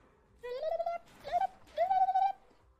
PLAY bill and ted guitar sound
guitarra-online-audio-converter.mp3